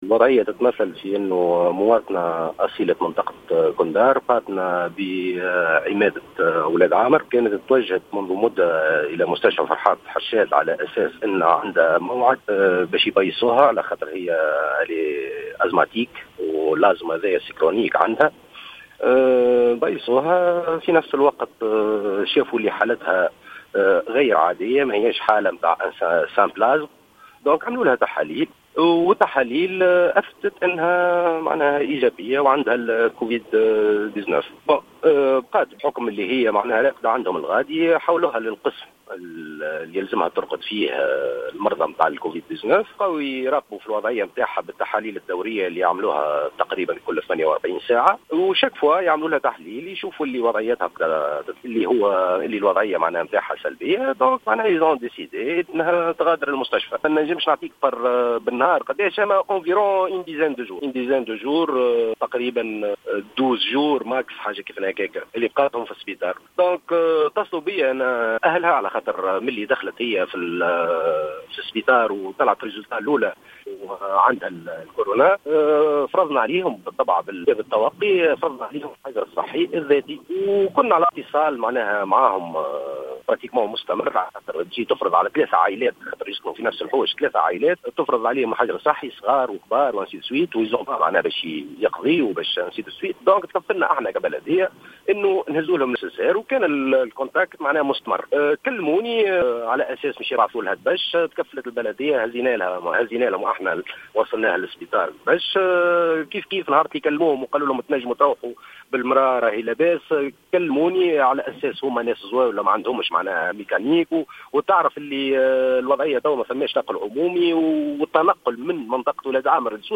أكد رئيس بلدية كندار علي بن ميم في تصريح للجوهرة اف ام مساء اليوم الأحد أن...